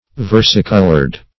Search Result for " versicolored" : The Collaborative International Dictionary of English v.0.48: Versicolor \Ver"si*col`or\, Versicolored \Ver"si*col`ored\, a. [L. versicolor; versare to change + color color.] Having various colors; changeable in color.